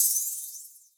OPENHAT (5_).wav